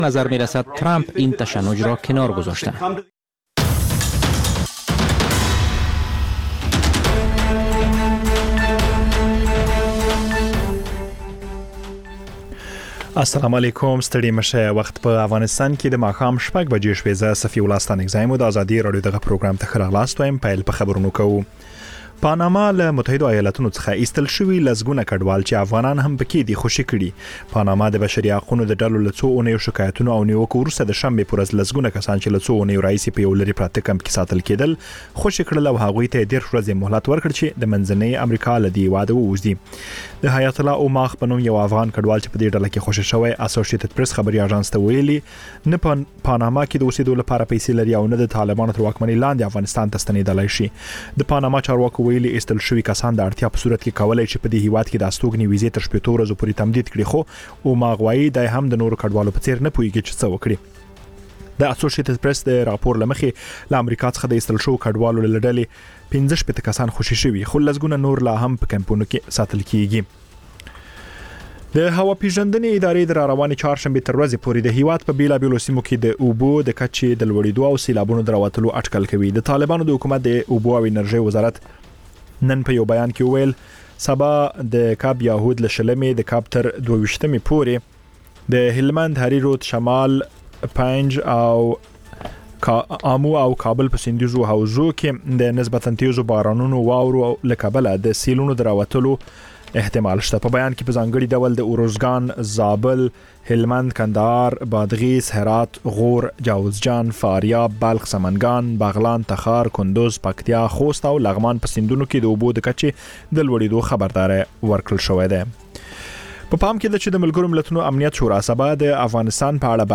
ماښامنۍ خبري مجله